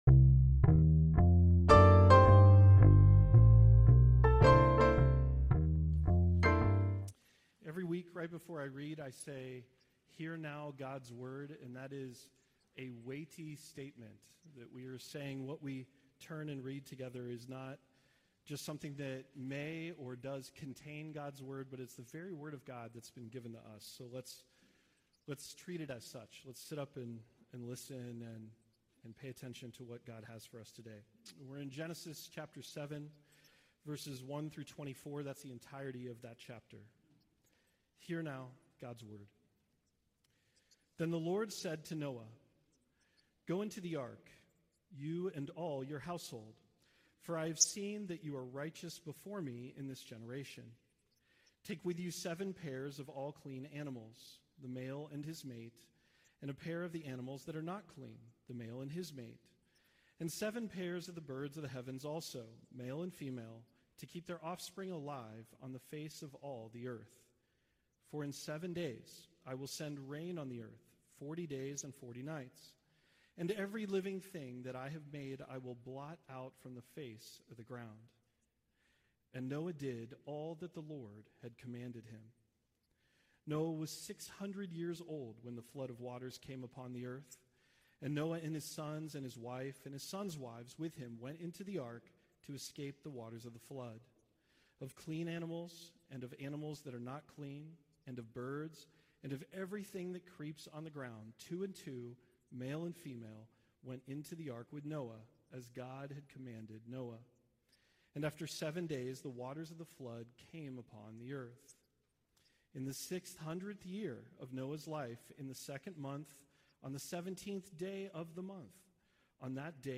Genesis 7:1-24 Service Type: Sunday Worship « From Beginning To Babel